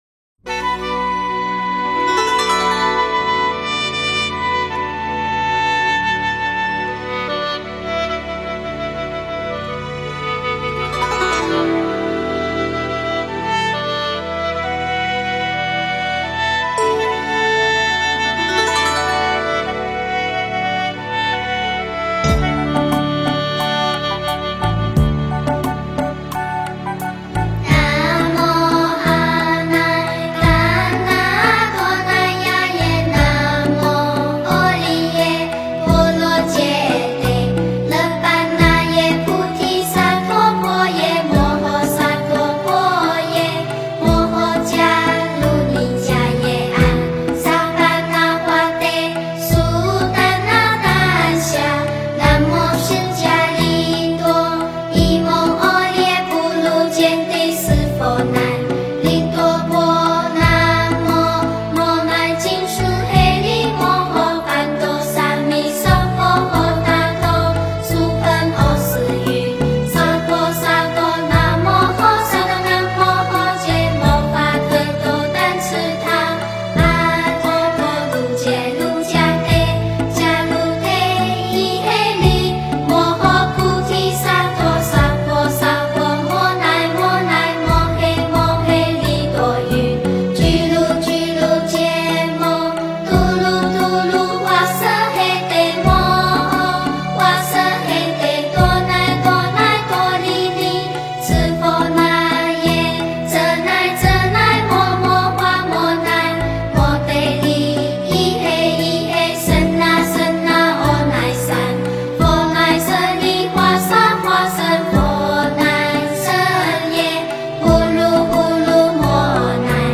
大悲咒(童音版)--佛教音乐